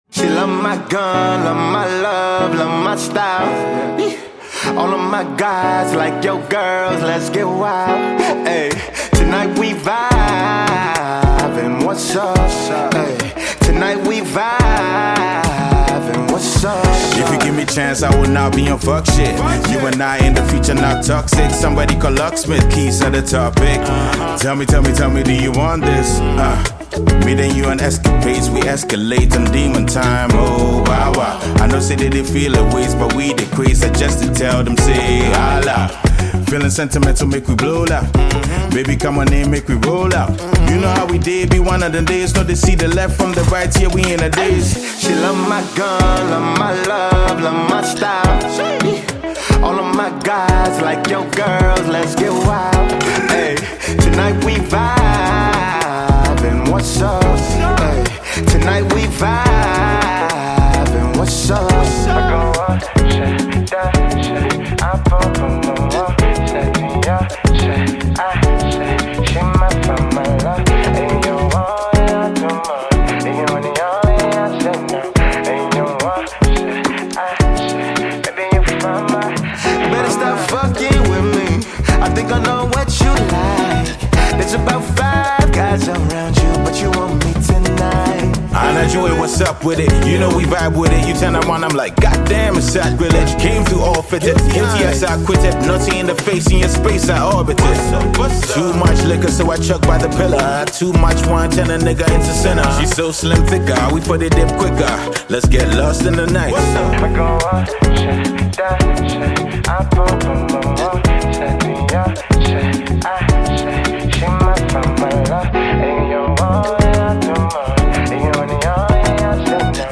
Ghana Music
Ghanaian rapper